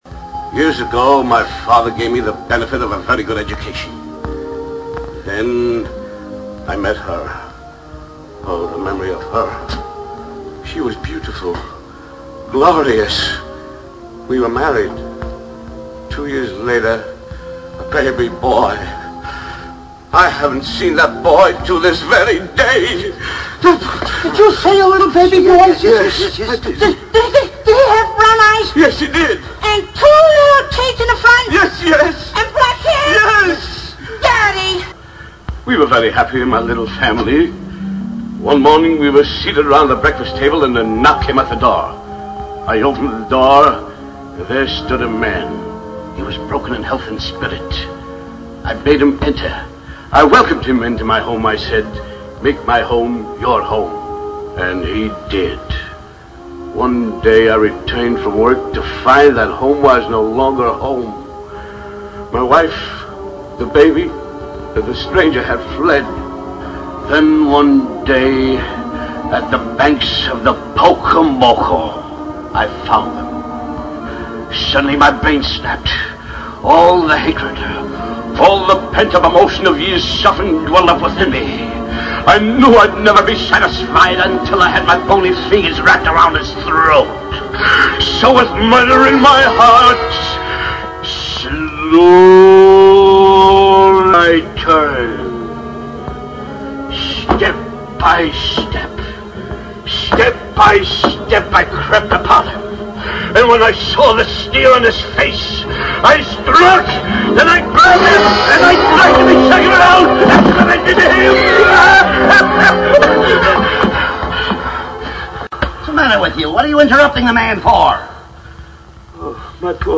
COMPLETE SKIT